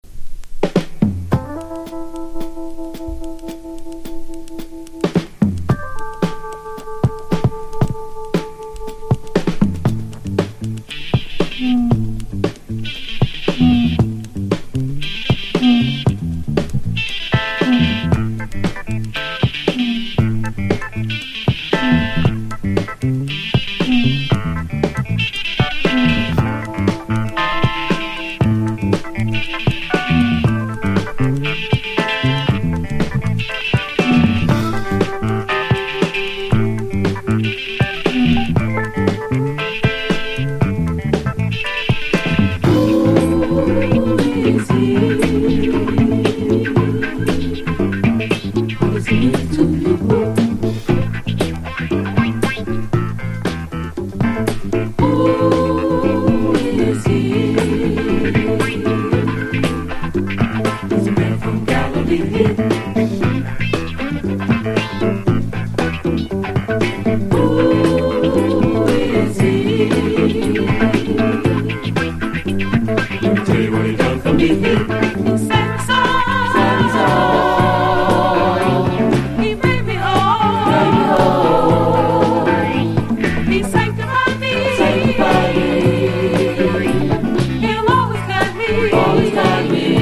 タイトル通りのゴスペル系のDISCOコンピ盤。